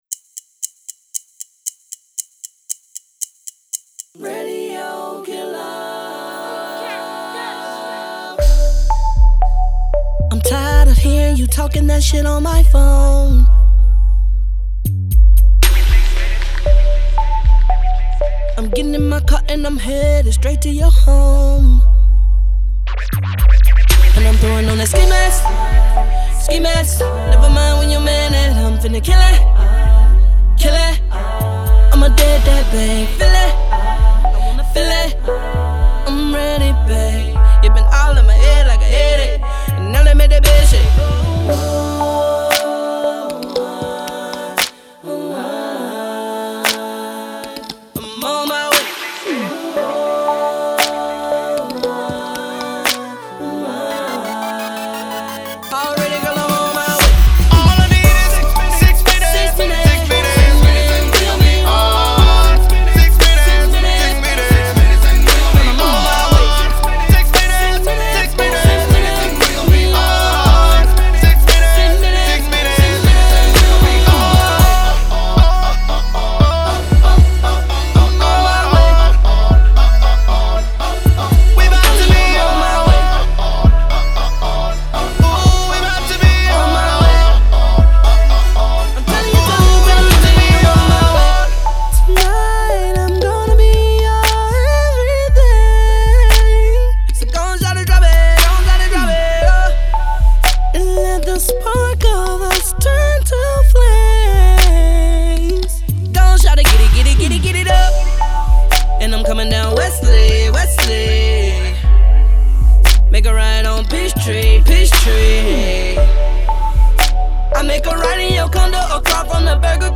RnB
RAW R&B FLOW TALENT